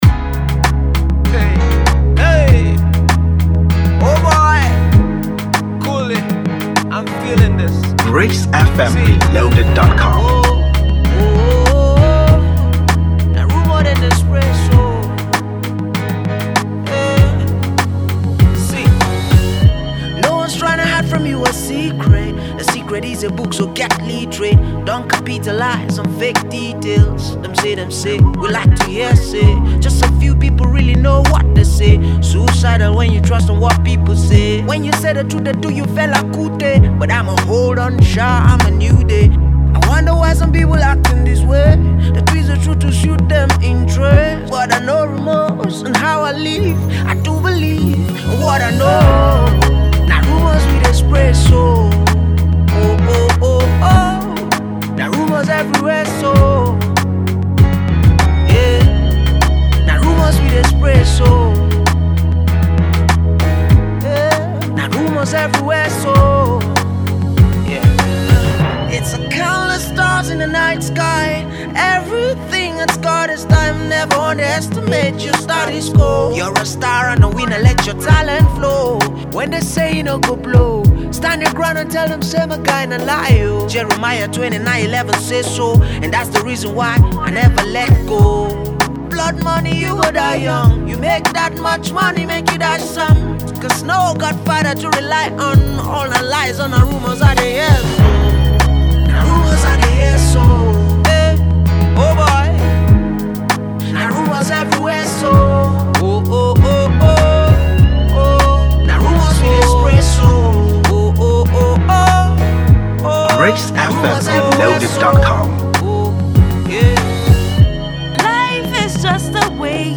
afrocentric
was recorded, mixed and mastered at the Lokoja studios